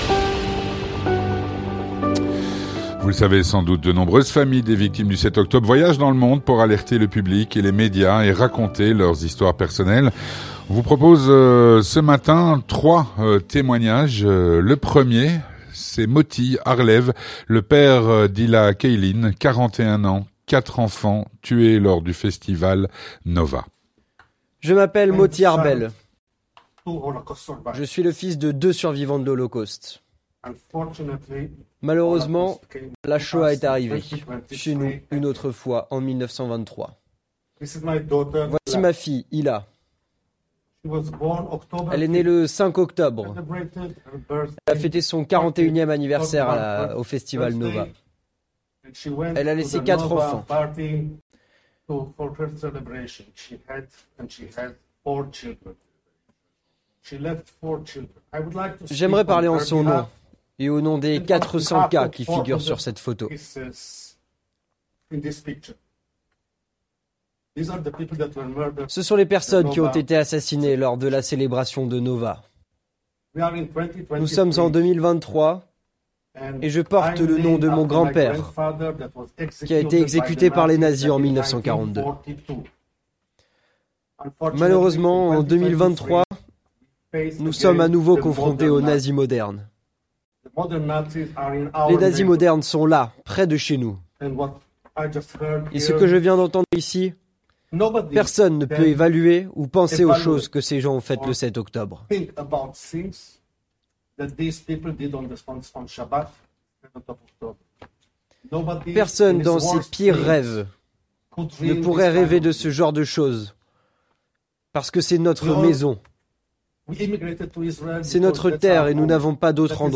Témoignages recueillis